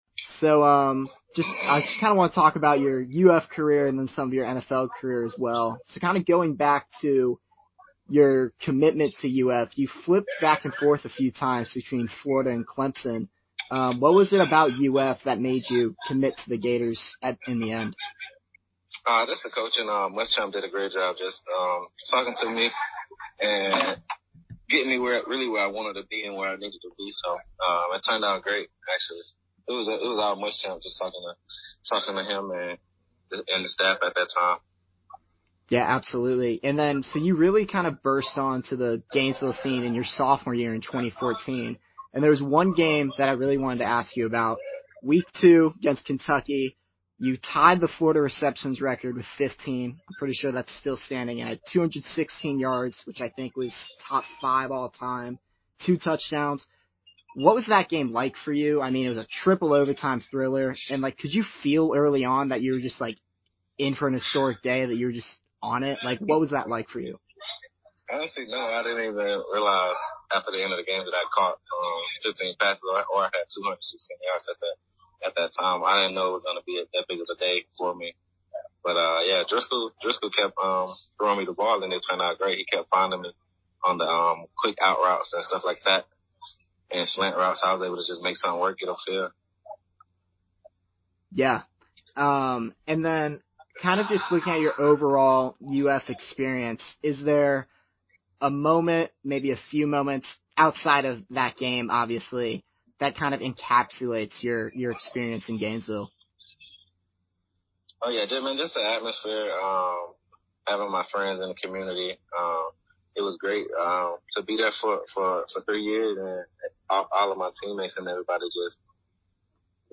Demarcus Robinson Interview